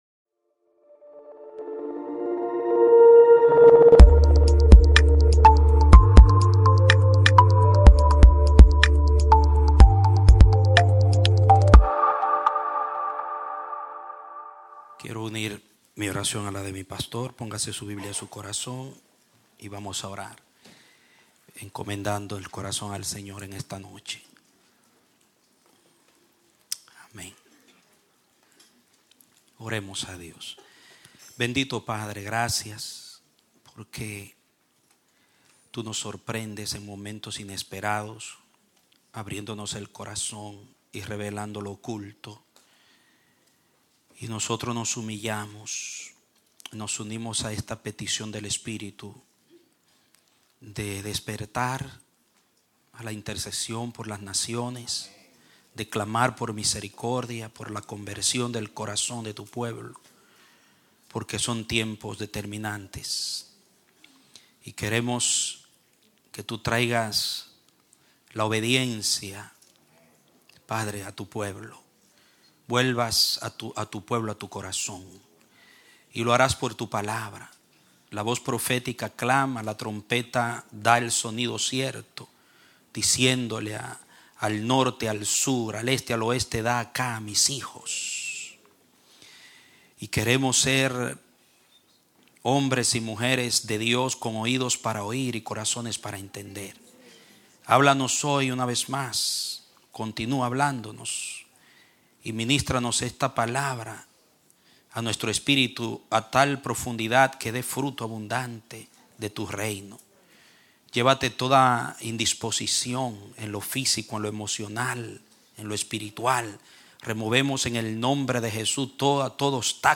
Un mensaje de la serie "Actitudes Peligrosas."